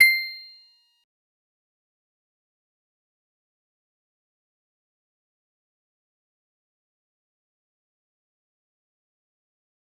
G_Musicbox-C8-mf.wav